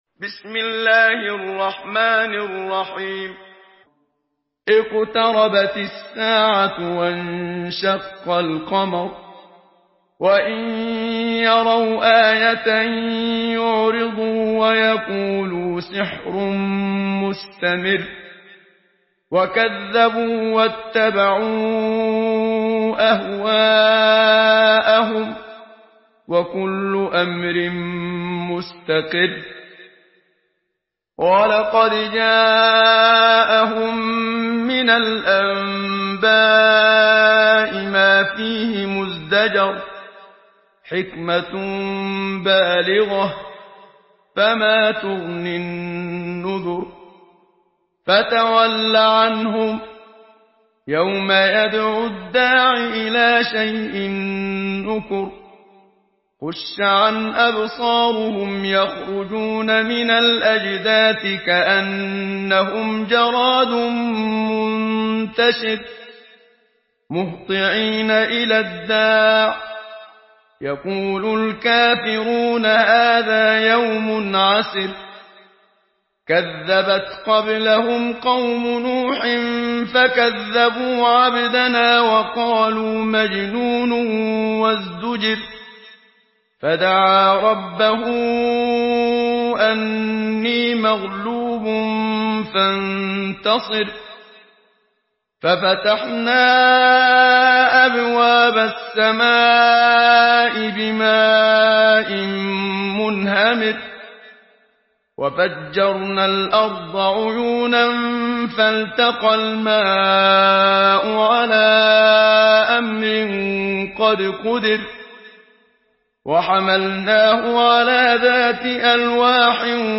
Surah القمر MP3 by محمد صديق المنشاوي in حفص عن عاصم narration.
مرتل